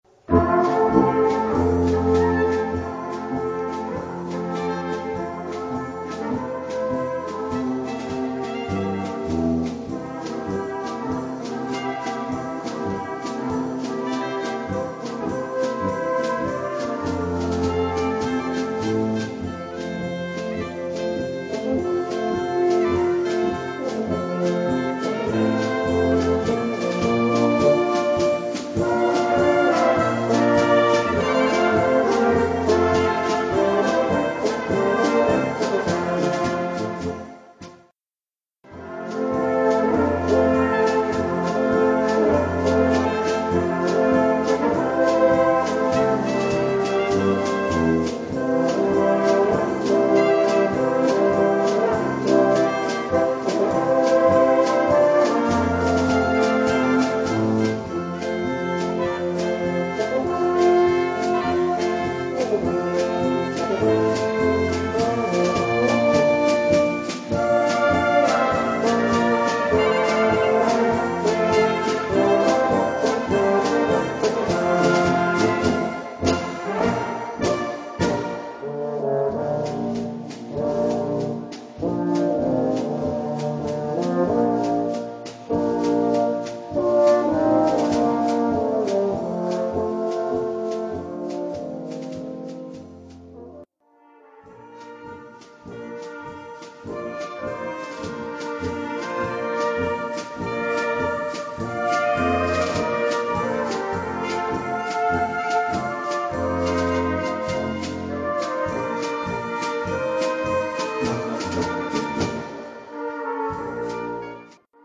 Unsere bunt zusammen gemischte Gruppe besteht aus ca. 16 Musikerinnen und Musikern aus Ballenberg, Krautheim, Gommersdorf, Erlenbach, Schöntal, Boxberg, Ulm, Unterwittstadt und Osterburken.
Wir spielen böhmisch-mährische Blasmusik, wie Polkas und Märsche, anspruchsvolle Solostücke, Potpourries, Evergreens, Stimmungsmusik aber auch moderne Arrangements in angenehmer Lautstärke.
Unser Gesangsduo